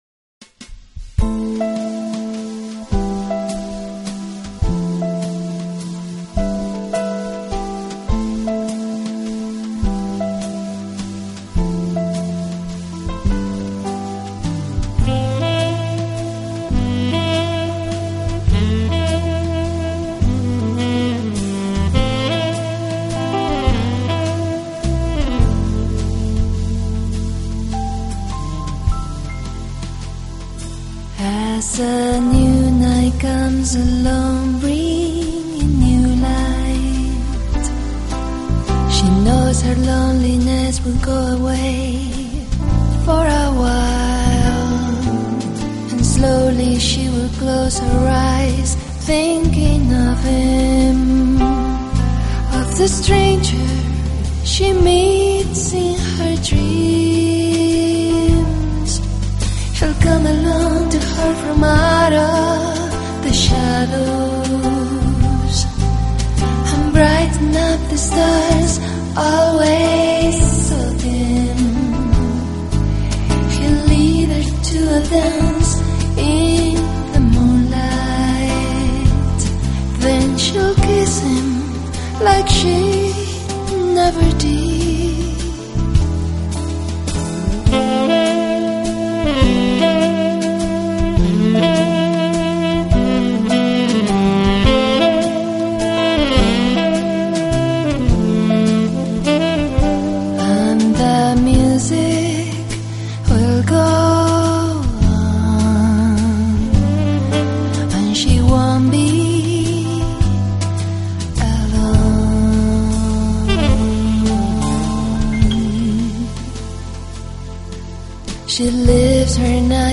【爵士专辑】
音乐类型：Jazz